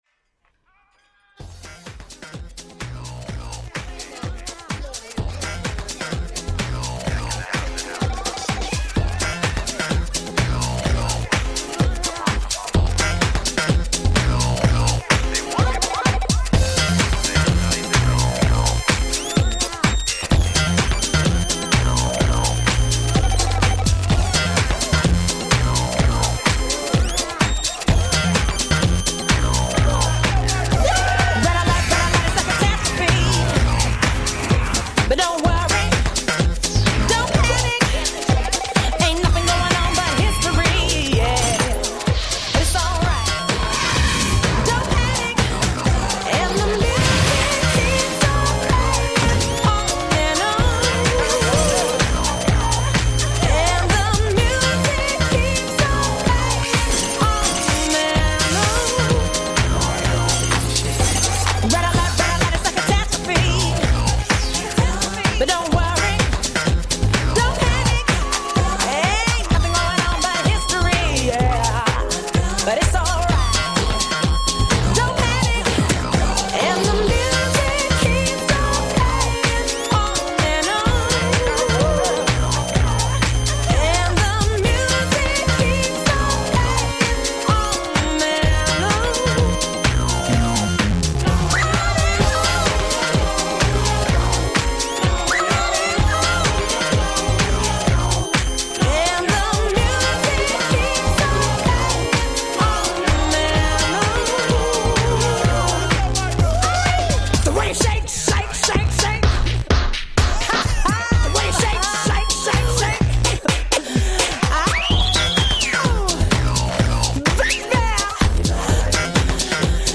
The Velvet Studio interviews the folks behind the big gay Prom in Ottawa